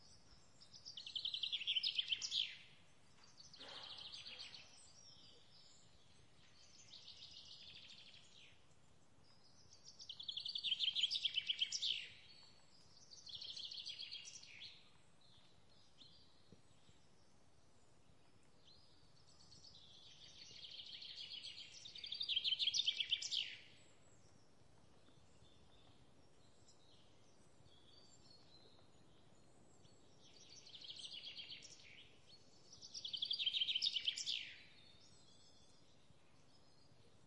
描述：泰国有虫子和鸟类的丛林森林（PhaNgan岛）
标签： 鸟类 臭虫 森林 泰国
声道立体声